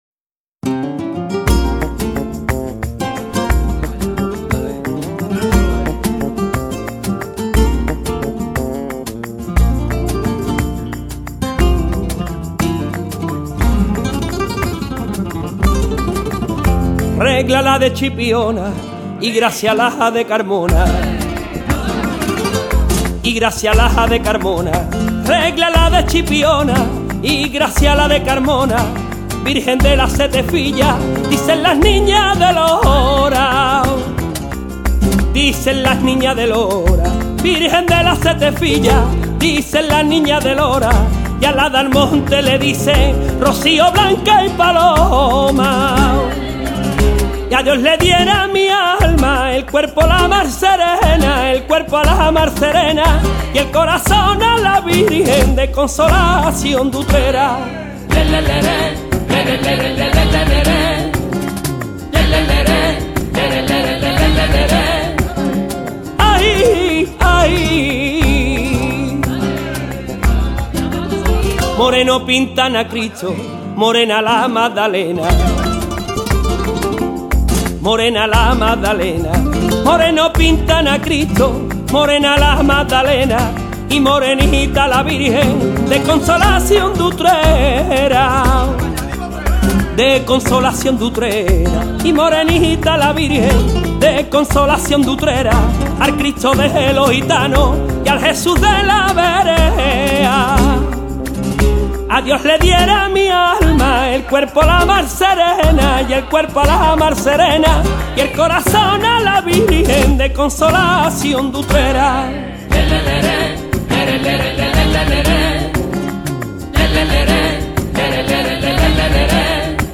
Sevillanas